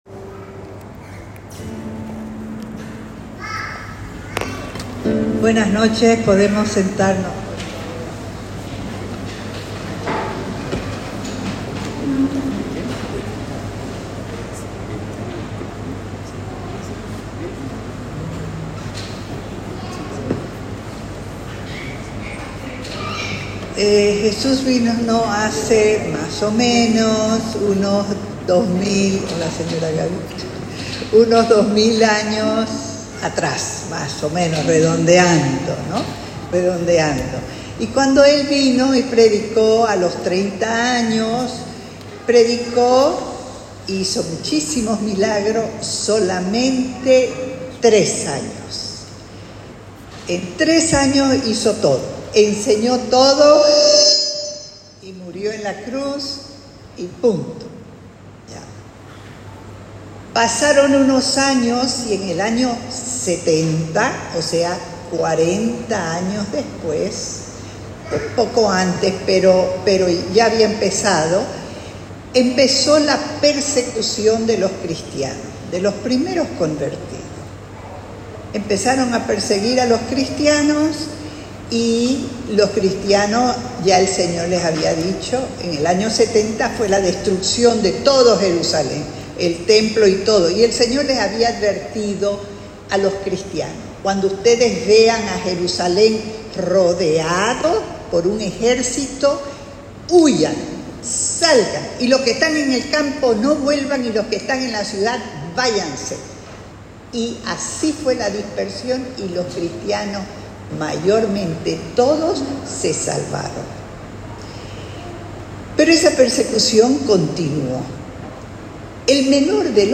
CICLO:Seminario de vida en el Espiritu &nbsp; Predica